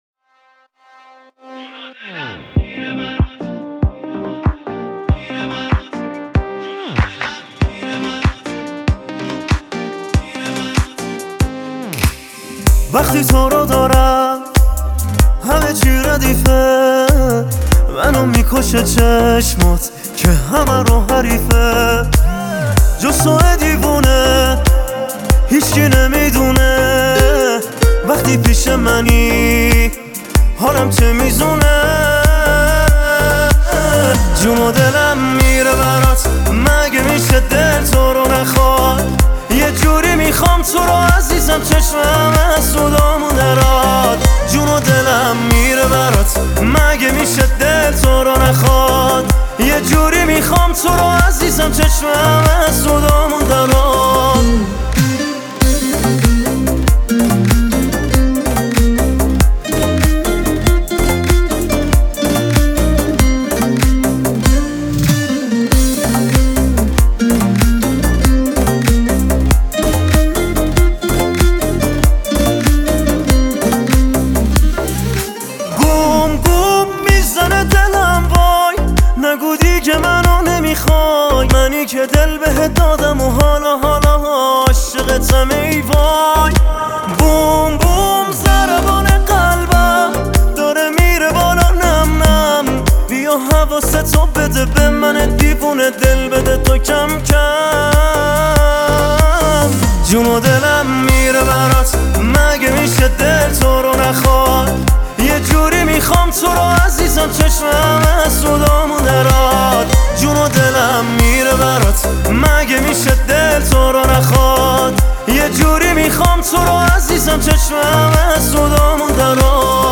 گیتار